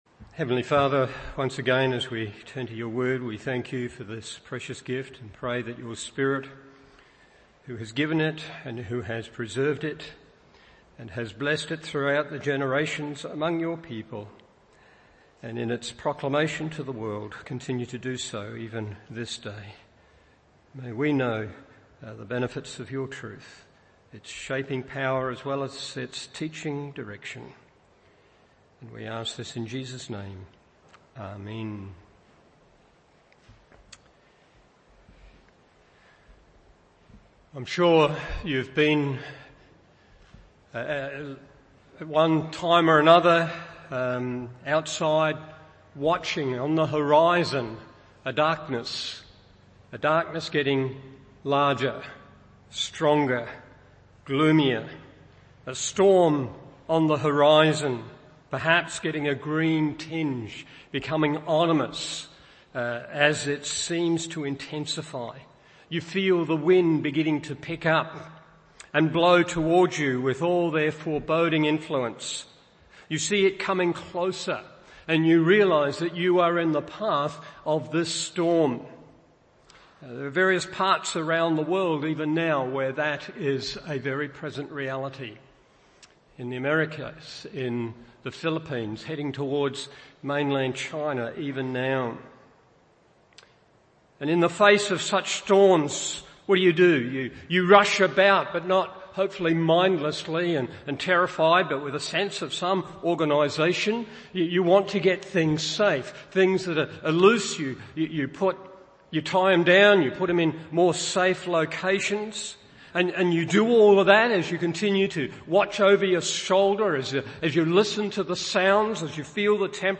Morning Service Genesis 7:6-24 1.